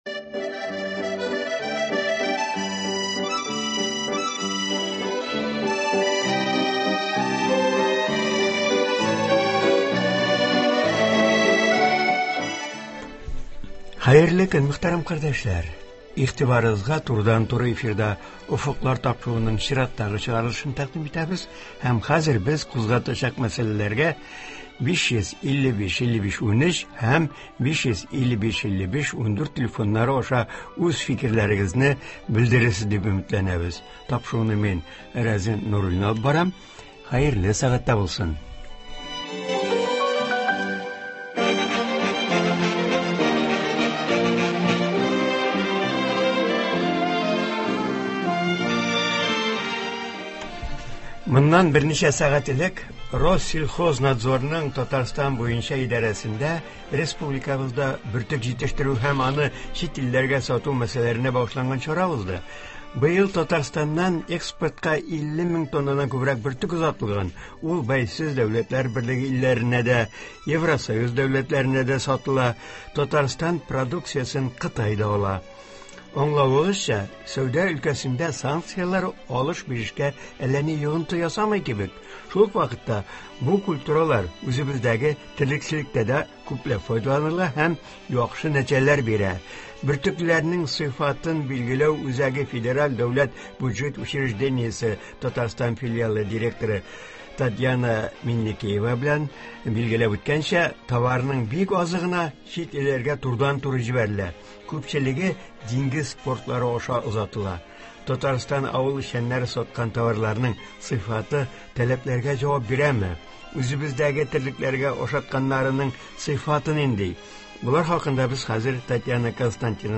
тыңлаучылар сорауларына җавап бирәчәк.